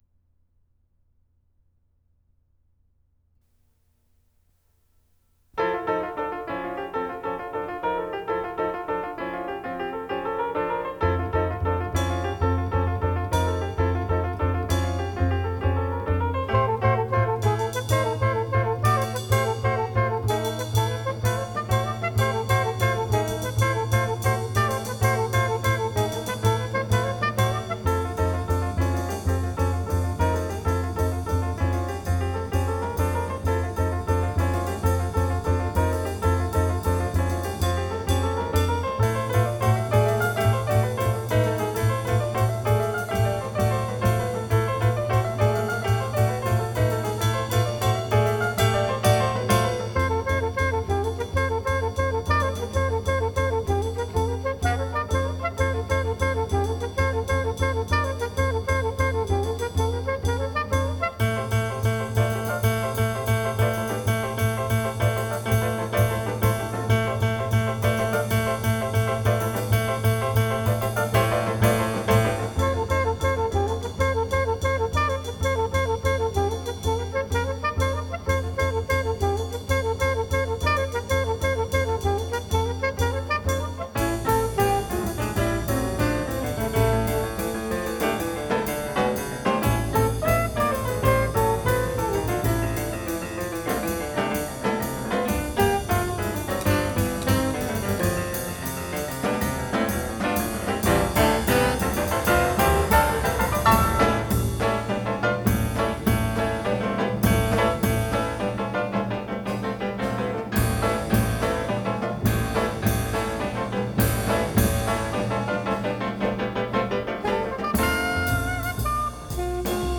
Piano
Alto Saxophone
Bass
Drums